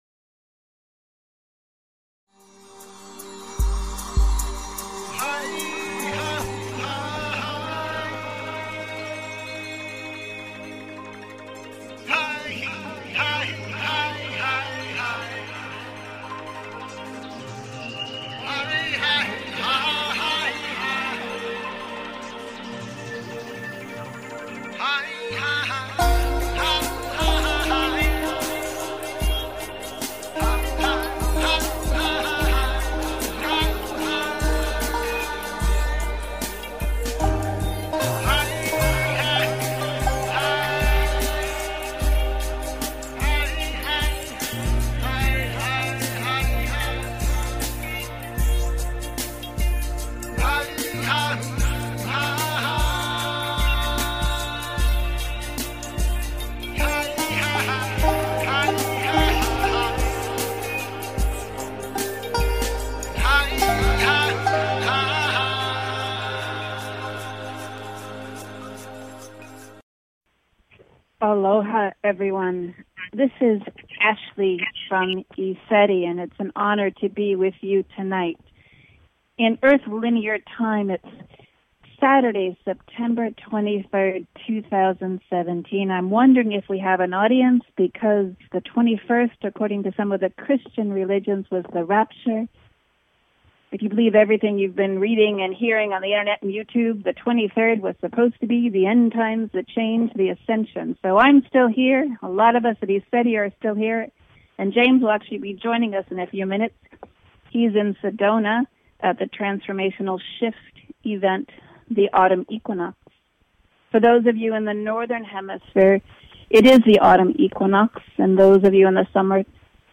Talk Show Episode, Audio Podcast
live at convention